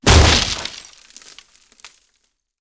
砍树2－YS070511.wav
通用动作/01人物/06工作生产/砍树2－YS070511.wav
• 声道 單聲道 (1ch)